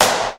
• Smooth Clap Single Hit G# Key 13.wav
Royality free clap - kick tuned to the G# note. Loudest frequency: 3165Hz
smooth-clap-single-hit-g-sharp-key-13-S6a.wav